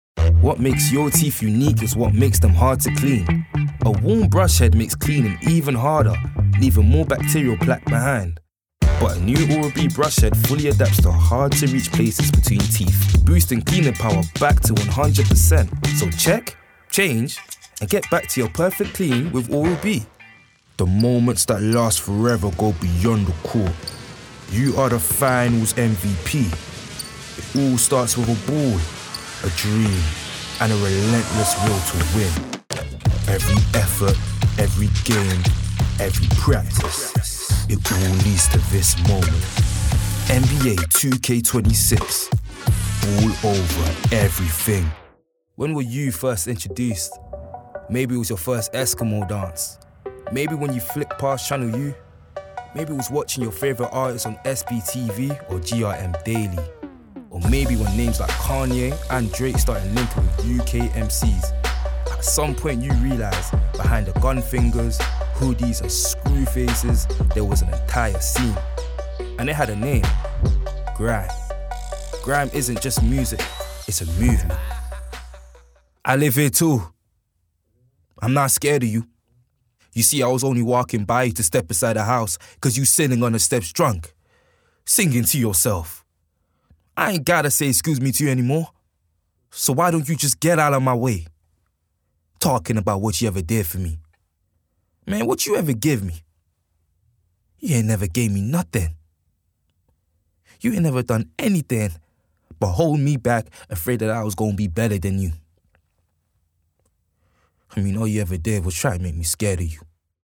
Native voice:
MLE
Voicereel: